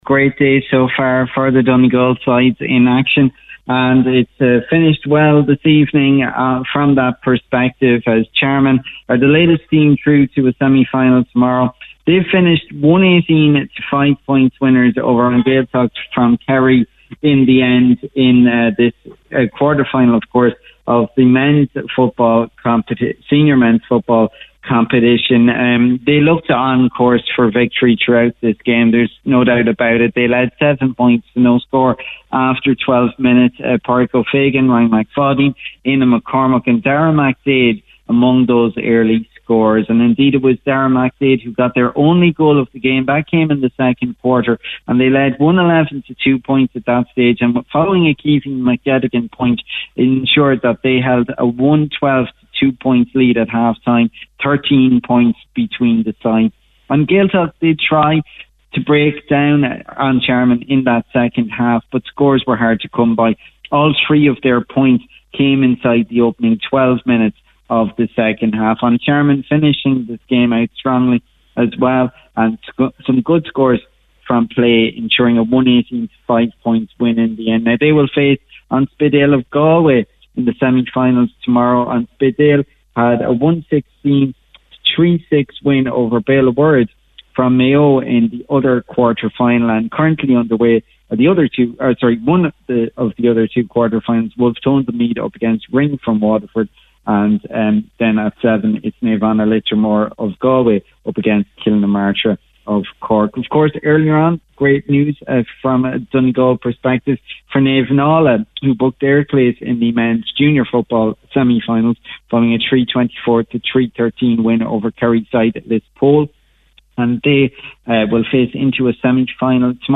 reported live from Rath Chairn